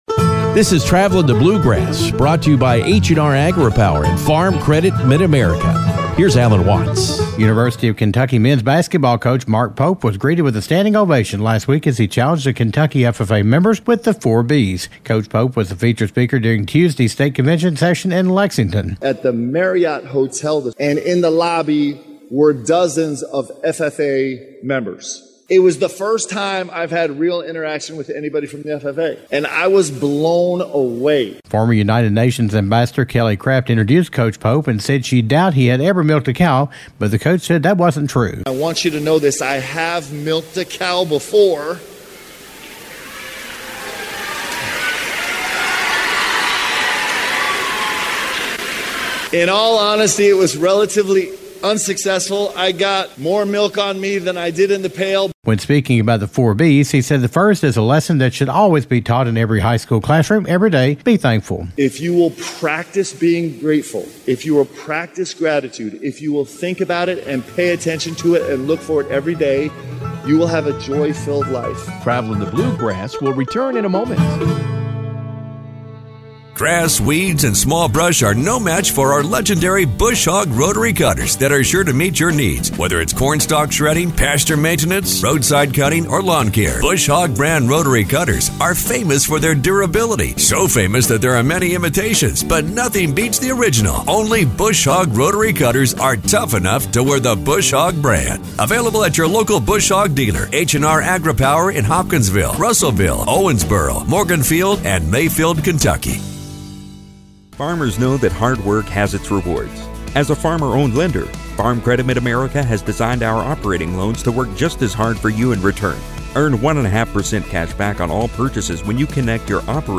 University of Kentucky Men’s Basketball Coach Mark Pope talked to Kentucky FFA members about the 4-B’s during the recent state convention. Coach Pope was the featured speaker for the Tuesday evening session of the State Convention in Lexington.